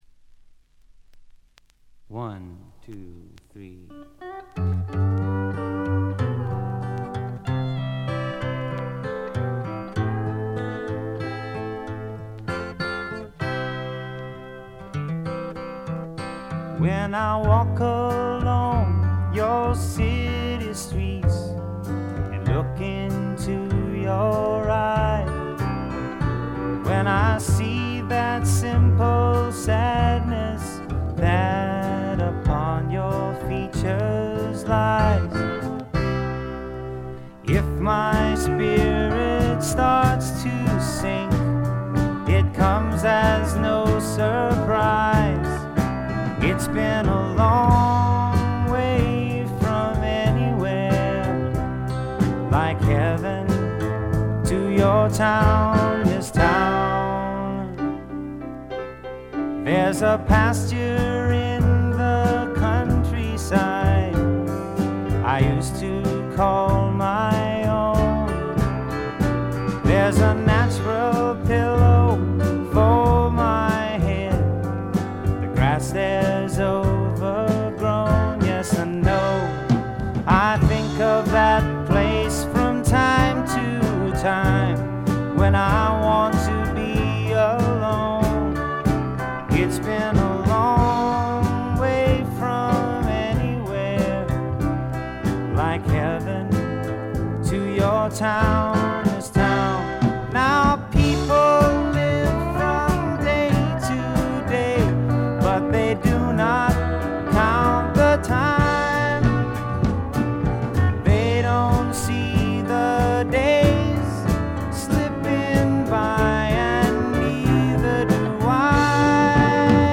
バックグラウンドノイズ、ところどころでチリプチ。
試聴曲は現品からの取り込み音源です。
Recorded at Sunset Sound, December '69